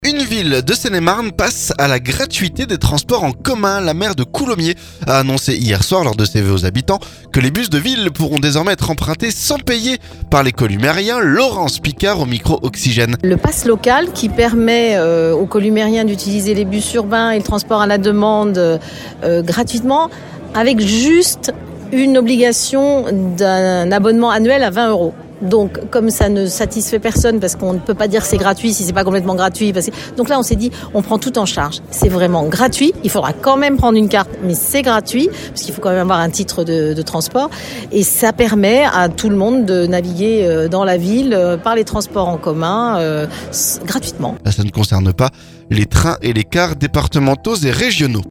La maire de Coulommiers a annoncé lundi soir lors de ses vœux aux habitants que les bus de ville pourront désormais être empruntés sans payer par les habitants. Laurence Picard au micro Oxygène.